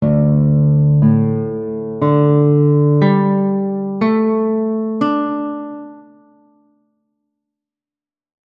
DADGAD Tuning
The second is to tune by ear to the following audio.
DADGAD-Tuning.wav